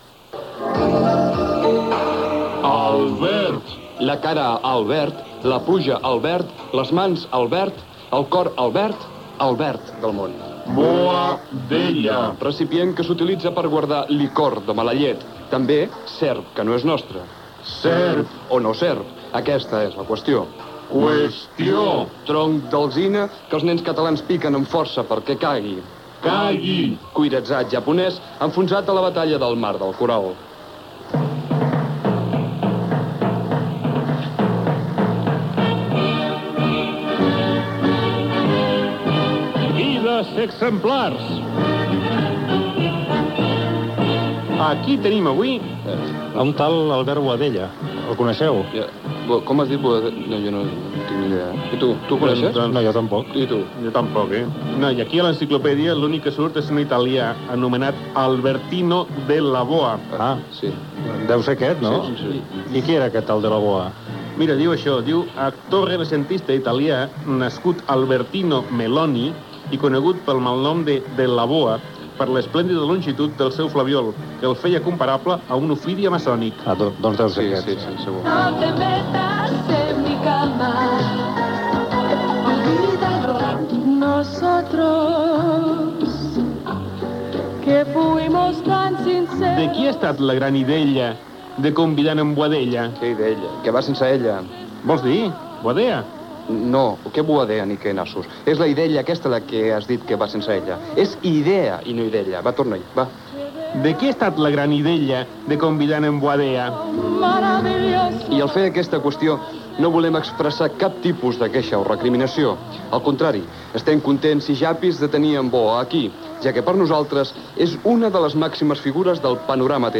Espai humorístic del col·lectiu Mans Brutes
Entreteniment